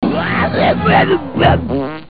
На этой странице собраны разнообразные звуки тасманийского дьявола – от агрессивного рычания до забавных вокализаций.
Звук легендарного Таза из мультфильмов, Тасманийский дьявол копирует